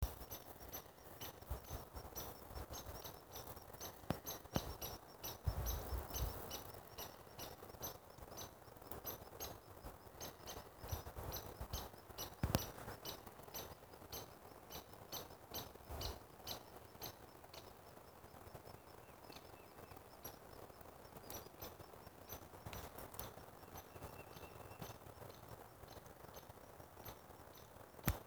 Hairy Woodpecker